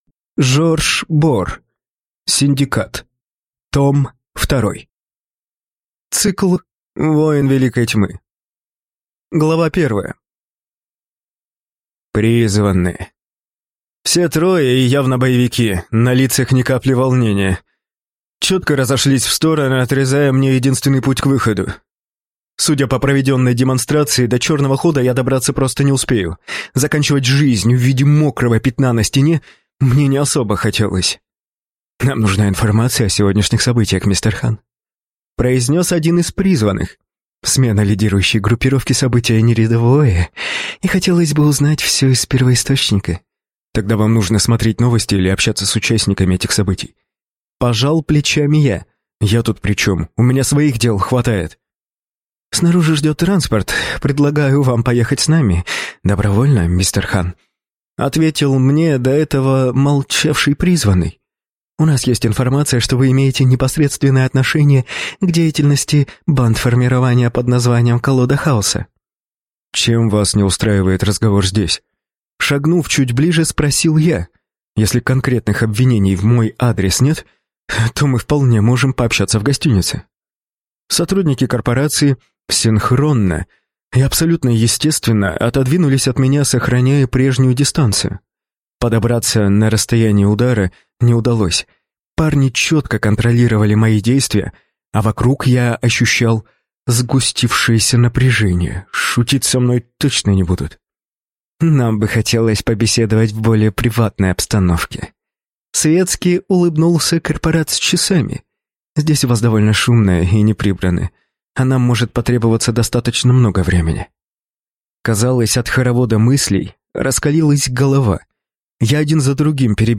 Аудиокнига Синдикат. Том 2 | Библиотека аудиокниг